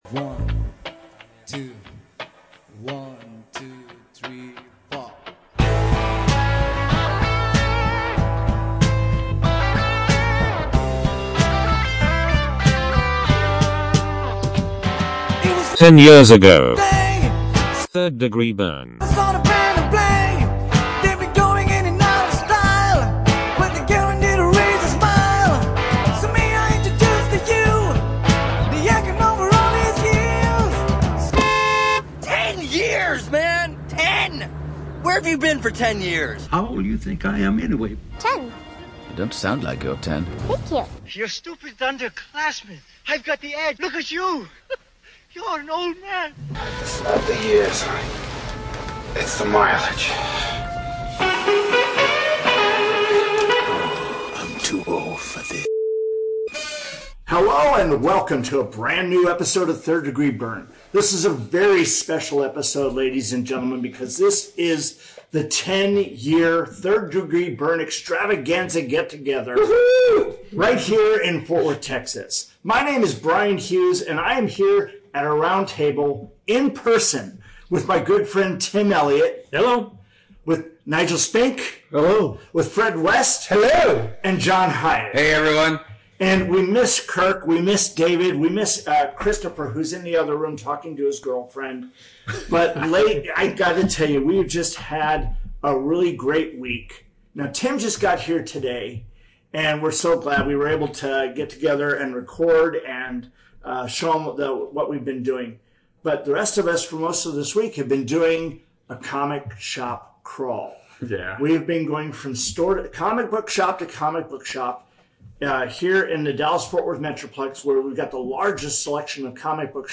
And we recorded a short reaction to our get together.